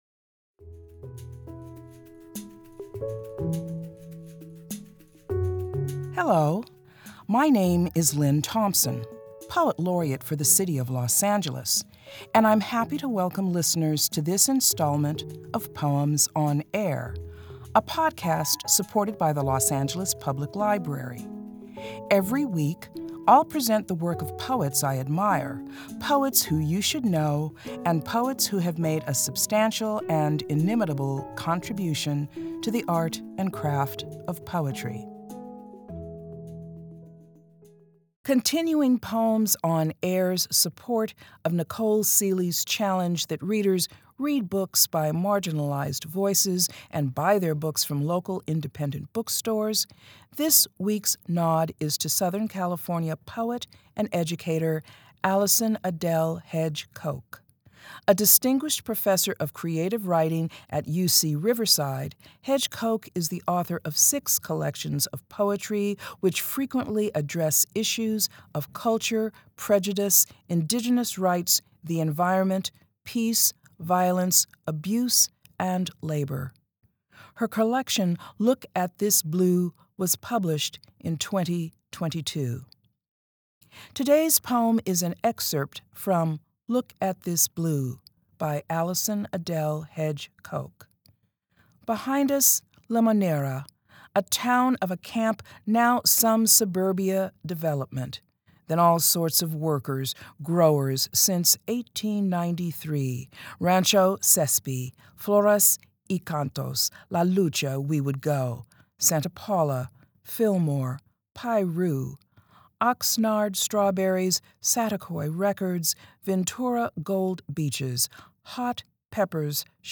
Los Angeles Poet Laureate Lynne Thompson reads Allison Adelle Hedge Coke's poem "Look At This Blue."